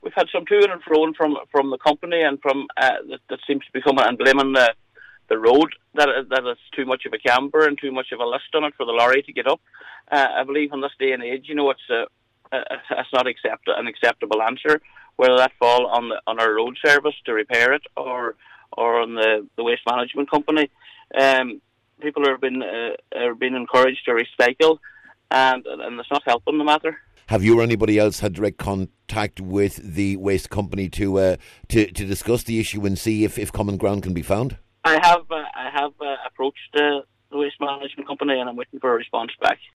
Councillor Martin Scanlon says the situation needs urgent attention from Donegal County Council.